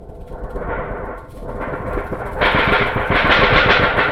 Index of /90_sSampleCDs/Roland L-CD701/PRC_FX Perc 1/PRC_Long Perc